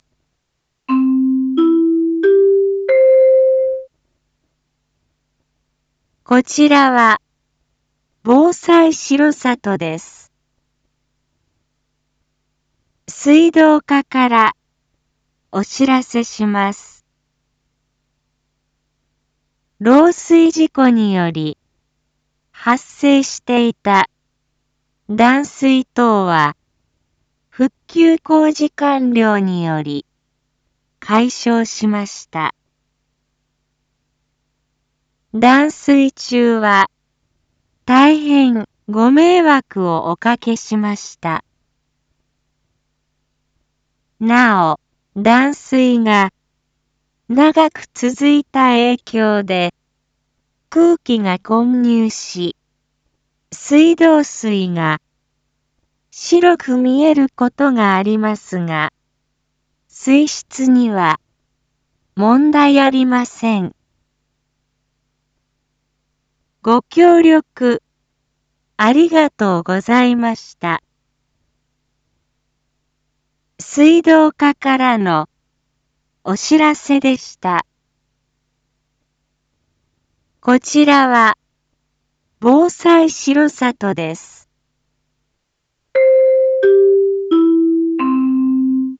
Back Home 一般放送情報 音声放送 再生 一般放送情報 登録日時：2023-05-19 13:38:30 タイトル：断水の解消について（上入野・増井・磯野地区） インフォメーション：こちらは、防災しろさとです。